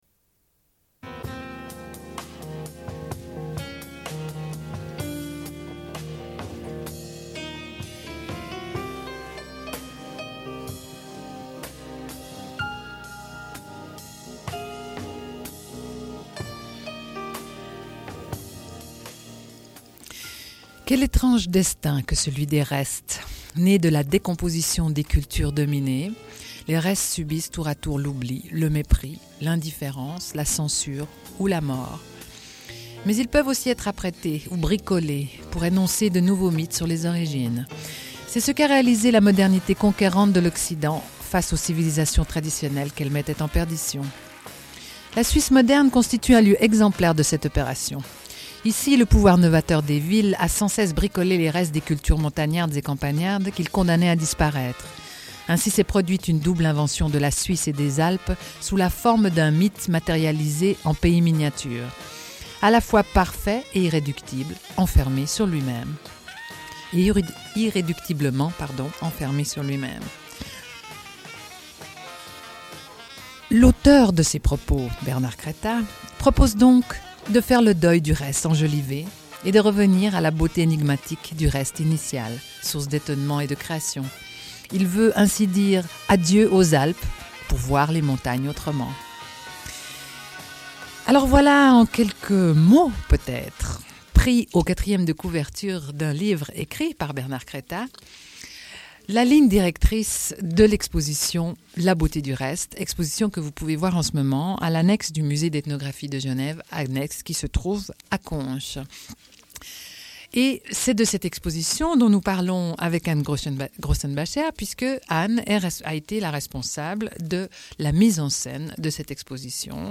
Une cassette audio, face B29:03
Radio